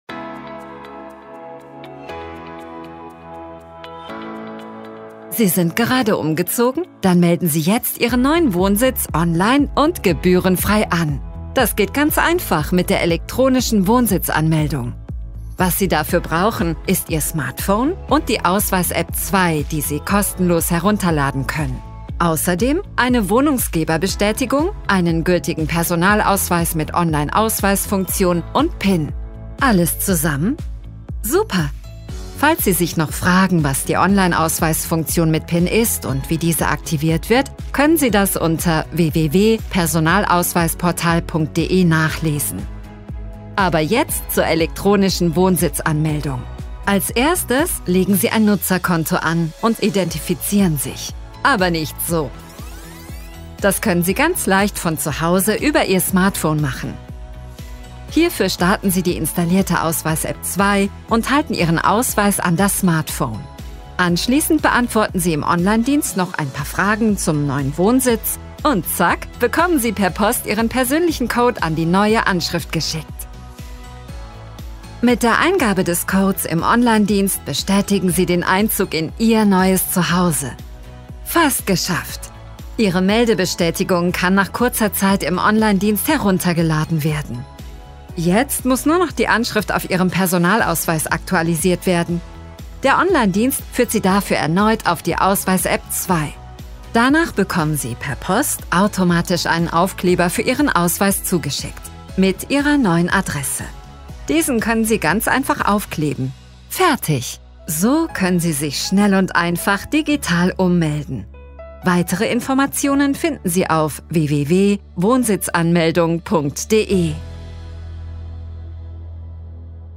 Explainer Videos
• Mikrofon: Neumann TLM 67 / Neumann TLM 103
• Acoustic Cabin : Studiobricks ONE
ContraltoMezzo-Soprano
ConfidentDynamicExperiencedFriendlyReliableTrustworthyVersatileYoungWarm